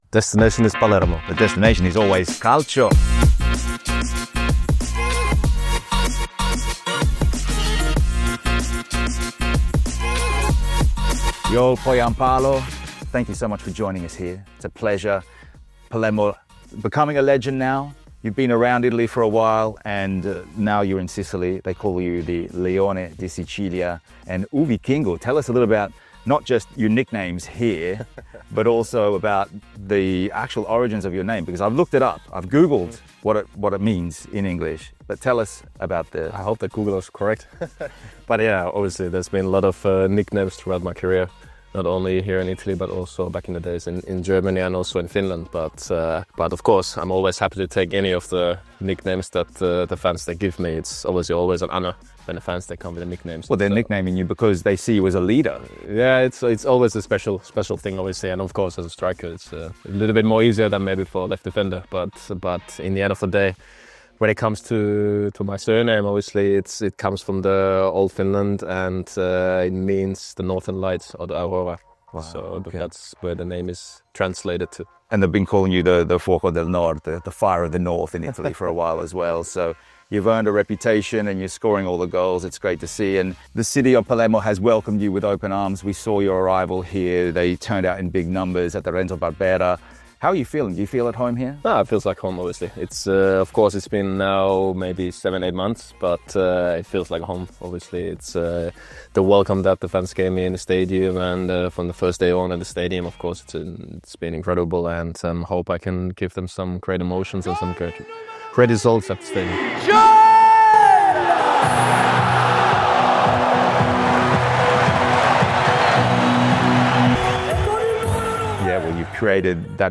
Palermo FC's Joel Pohjanpalo: The Finnish Striker Leading The Rosanero Promotion Push | Exclusive Interview